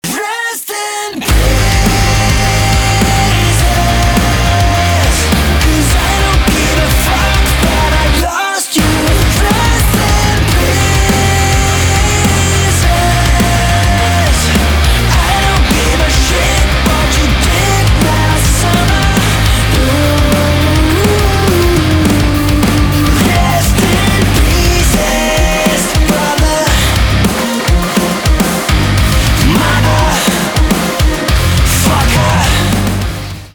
альтернатива
громкие , гитара , барабаны , качающие , грустные